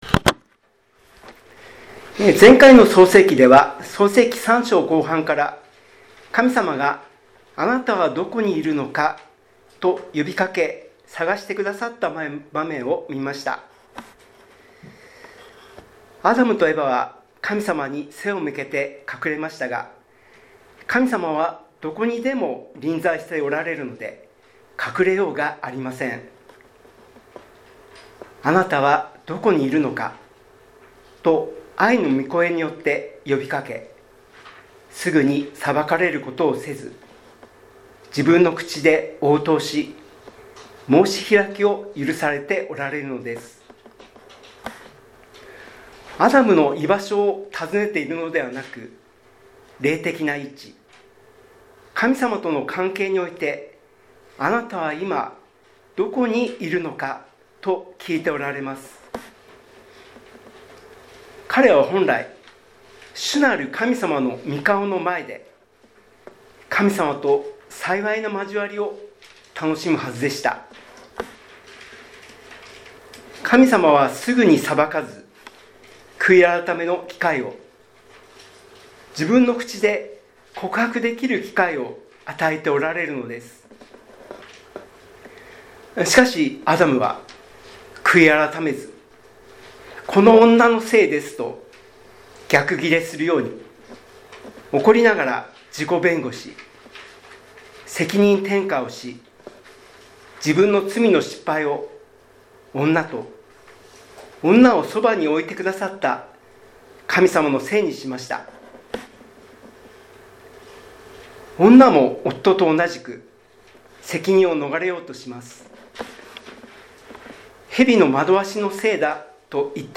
礼拝メッセージ